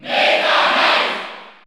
Category: Crowd cheers (SSBU) You cannot overwrite this file.
Meta_Knight_Cheer_French_NTSC_SSBU.ogg